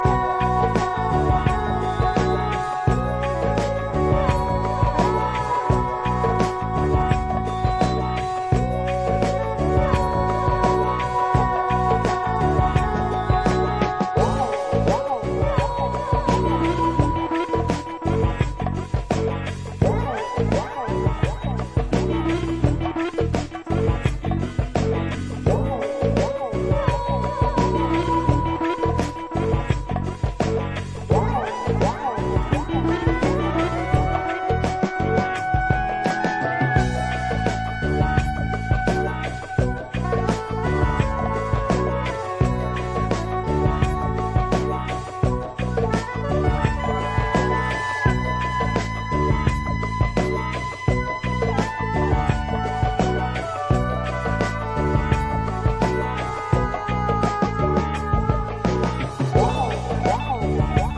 all of the downtempo variety